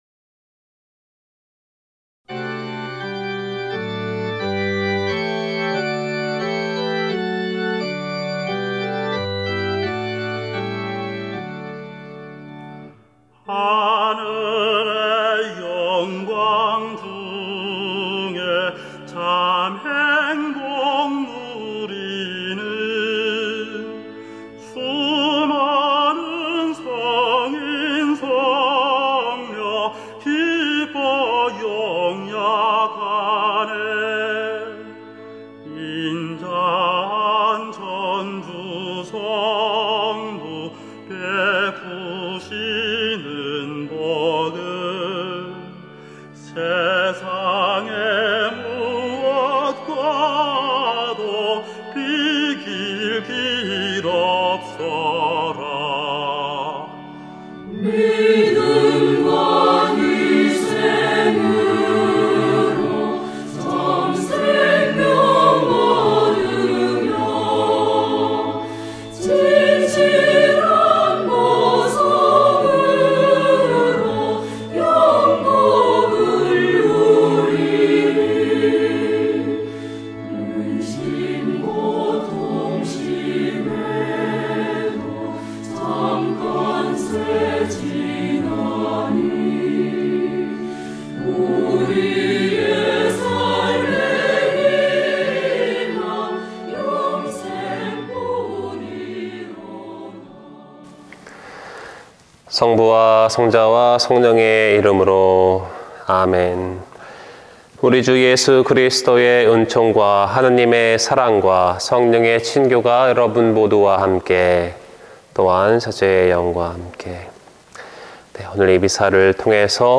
[오디오] 모든 성인 대축일 (11/1/2020) 미사